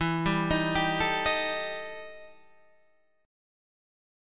EmM7bb5 chord